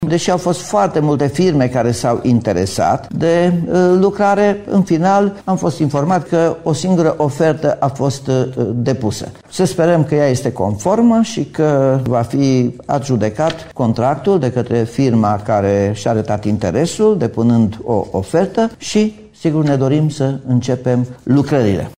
Primăria urmează să decidă acum dacă oferta este conformă cu cerințele, spune primarul Nicolae Robu.